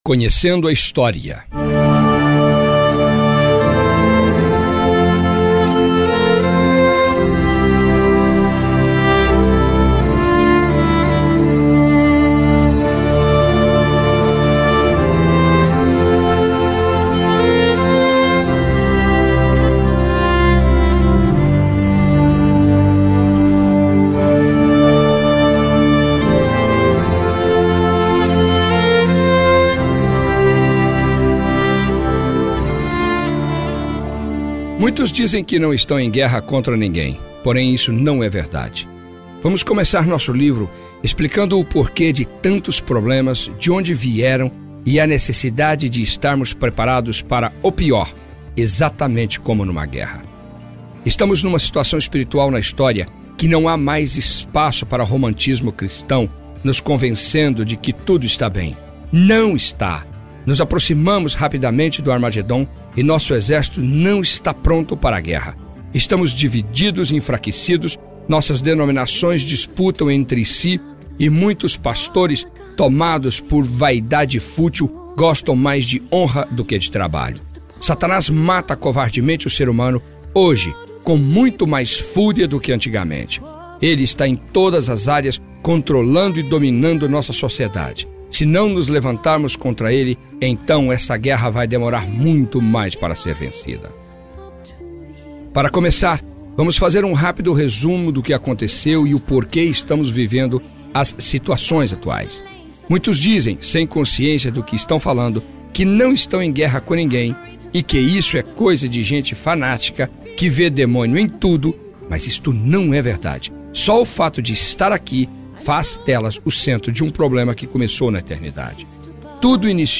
AudioBook – Dons Espirituais Como Armas De Guerra